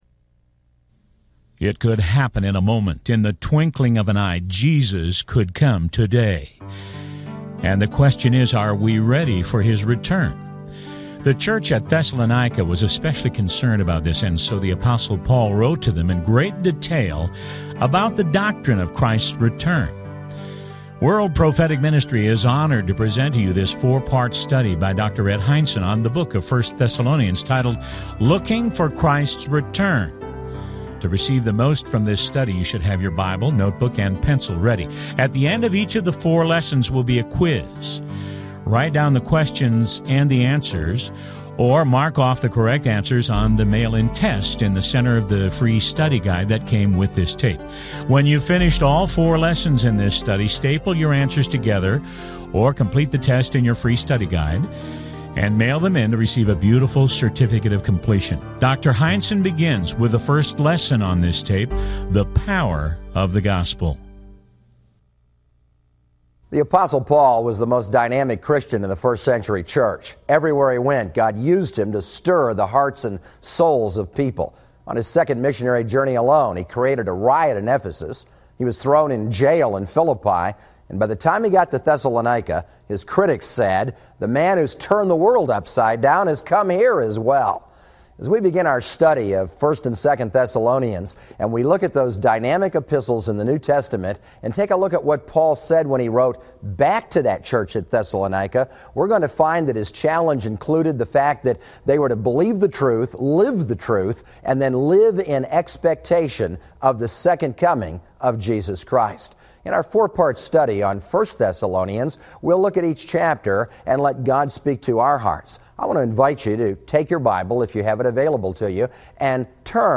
Talk Show Episode, Audio Podcast, The King Is Coming and Looking For Christs Return on , show guests , about Looking For Christs Return, categorized as Education,Health & Lifestyle,History,Love & Relationships,Philosophy,Religion,Christianity,Self Help,Society and Culture